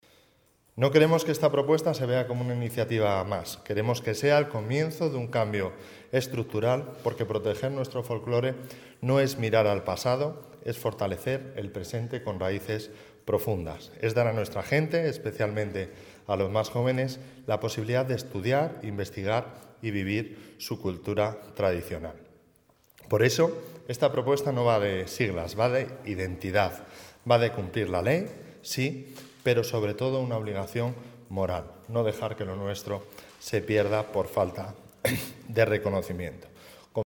El diputado regionalista Javier López Estrada durante la rueda de prensa que ha ofrecido hoy.
Ver declaraciones de Javier López Estrada, diputado del Partido Regionalista de Cantabria.